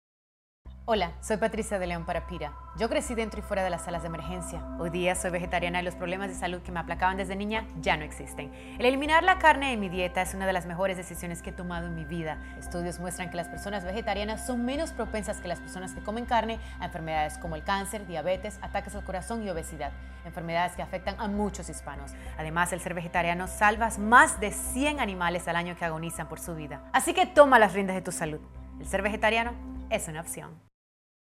Instructions for Downloading This Radio PSA Audio File
PatriciaDeLeon_spanish_final.mp3